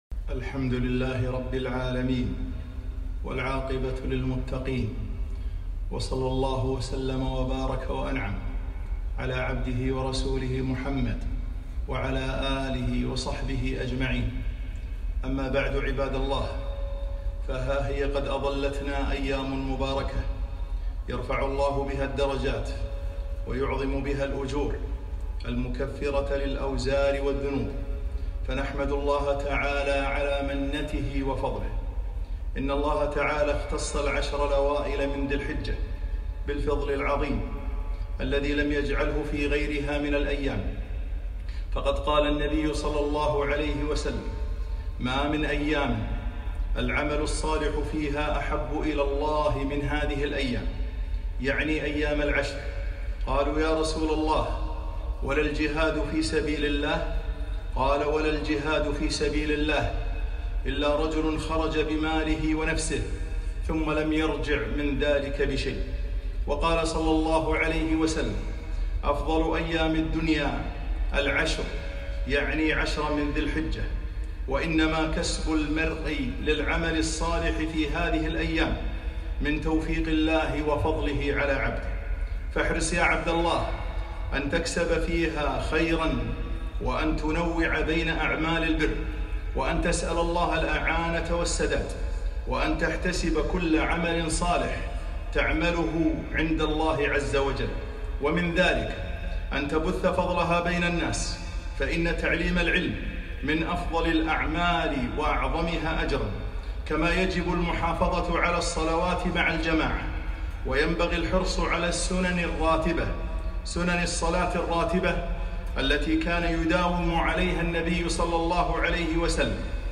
خطبة - حصاد الاجور في عشر ذي الحجة - دروس الكويت